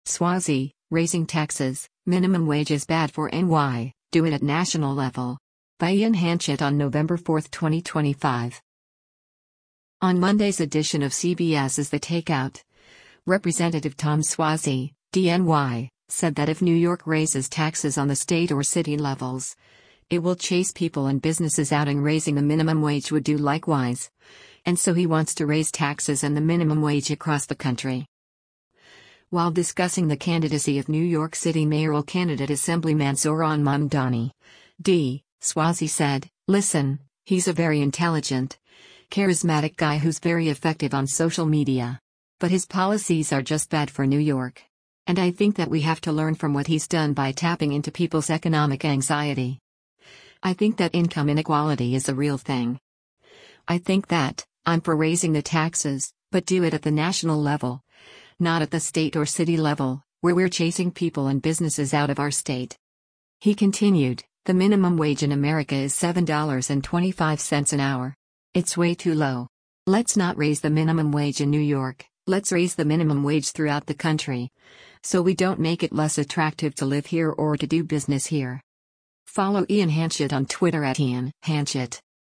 On Monday’s edition of CBS’s “The Takeout,” Rep. Tom Suozzi (D-NY) said that if New York raises taxes on the state or city levels, it will chase people and businesses out and raising the minimum wage would do likewise, and so he wants to raise taxes and the minimum wage across the country.